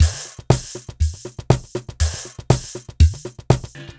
Under Cover (Drums) 120BPM.wav